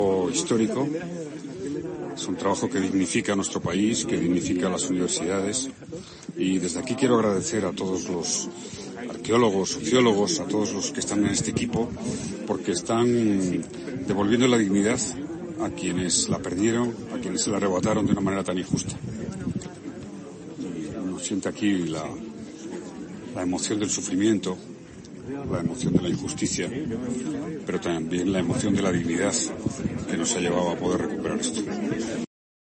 En un audio remitido por la institución académica a los medios, Zapatero ha señalado que esta tarea científica "dignifica" a España.